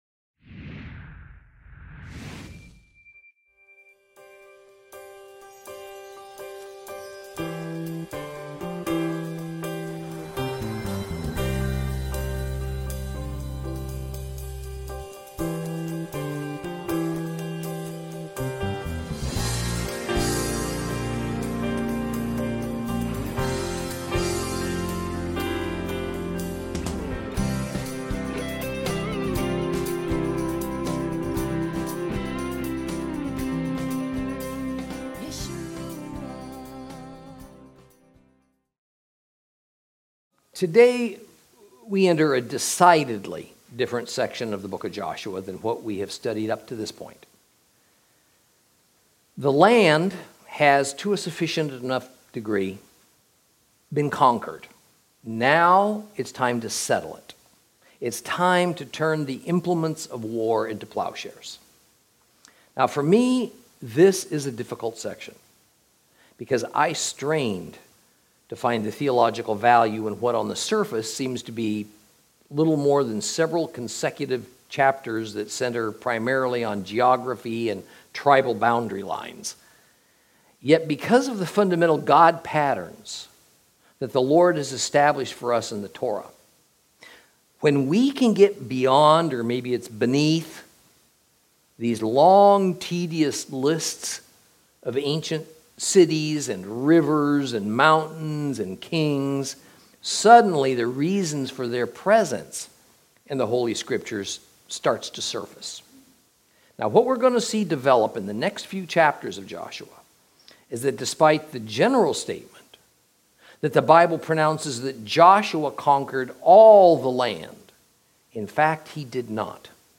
Lesson 17 Ch12 Ch13 - Torah Class